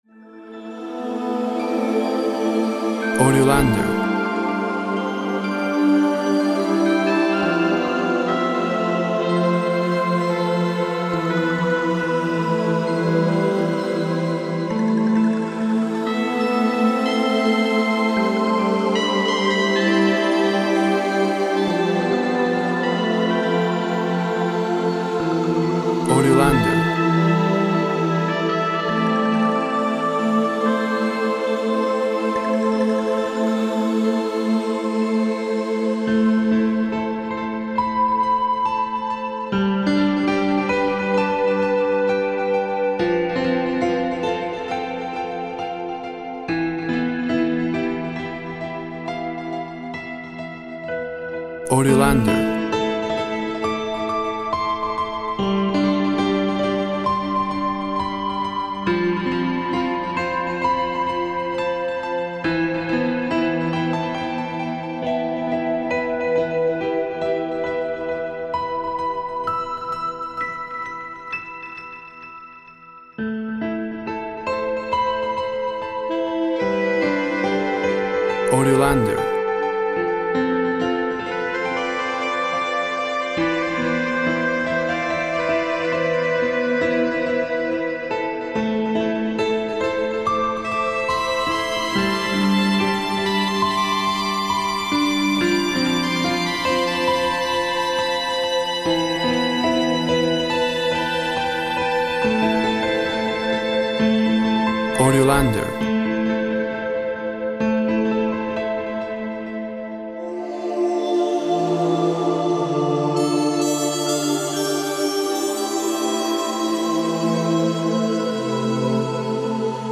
Soft mix of instruments creates a brooding atmosphere.
Tempo (BPM) 60